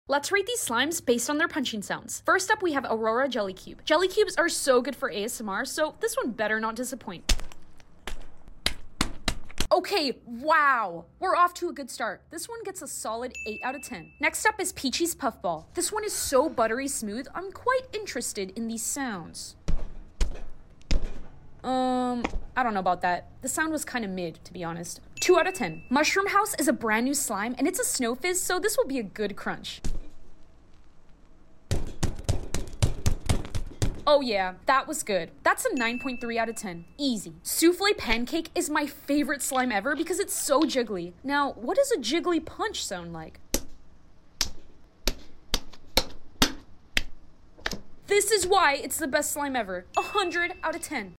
What slime had the BEST punching sound?